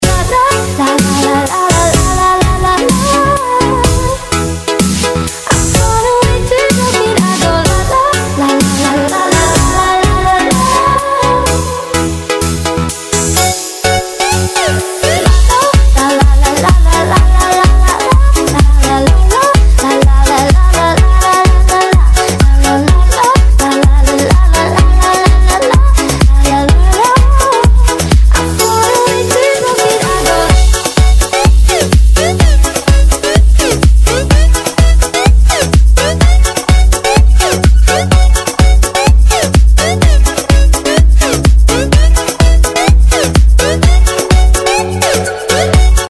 • Качество: 192, Stereo
с прекрасным женским вокалом!